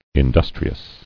[in·dus·tri·ous]